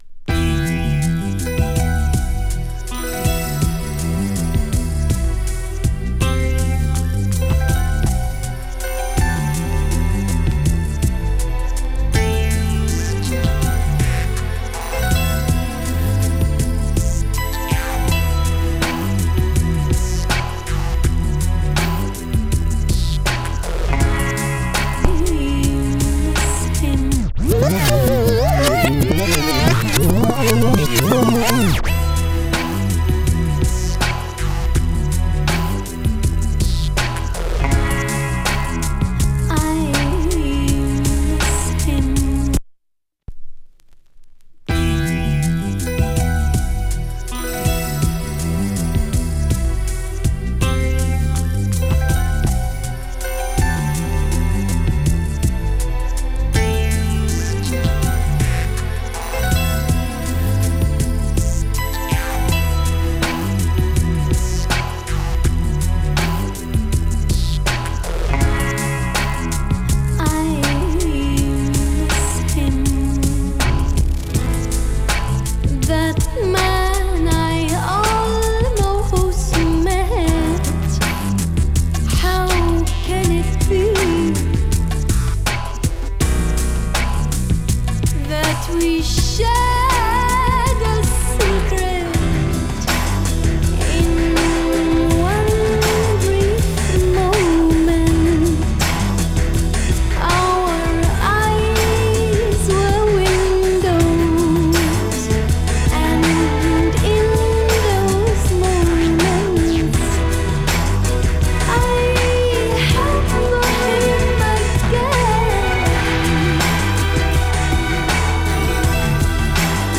美しくドラマチックに奏でるオリエンタルなストリングスと妖艶なヴォーカルが映えるブレイクビーツ